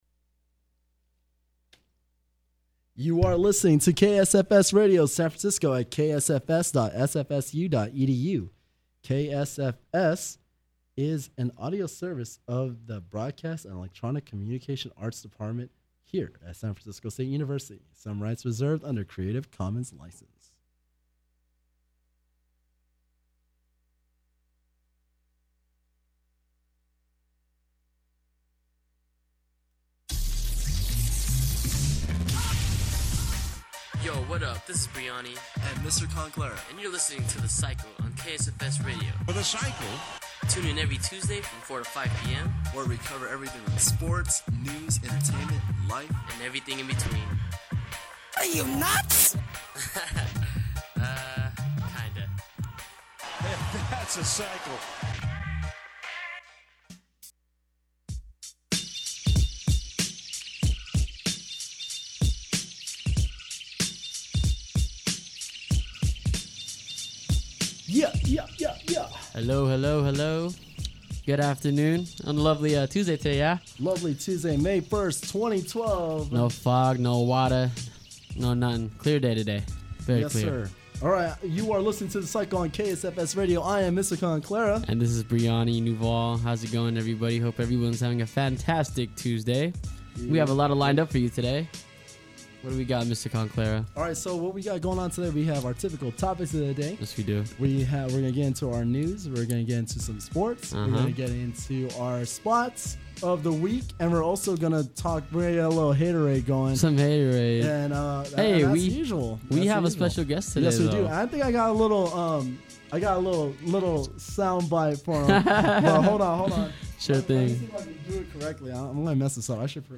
He asked me if I would be a guest on the show and I immediately said yes.